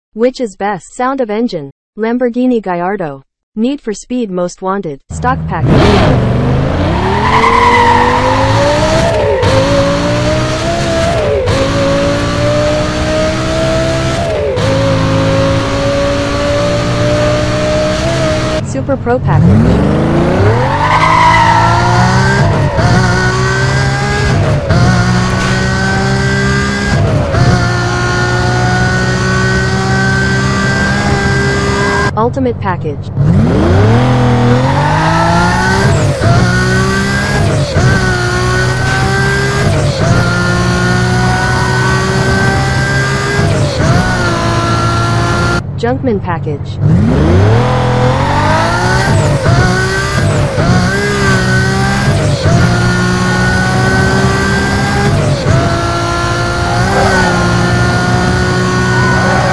Gallardo : NFSMW sound effects free download